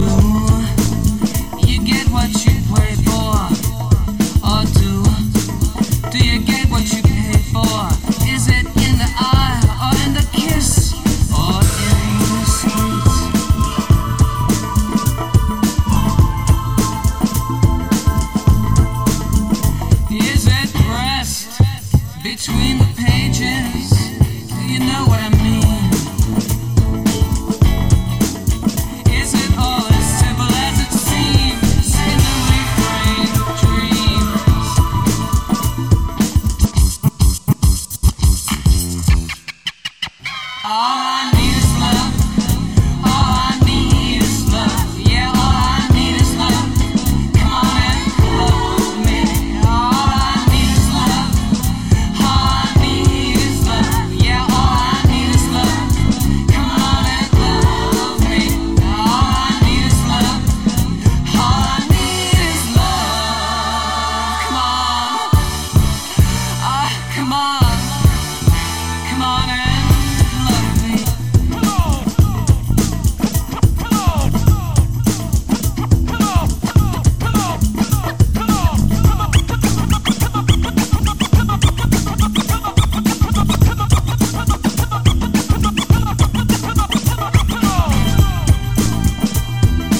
ジャンル(スタイル) DEEP HOUSE / DISCO / SOUL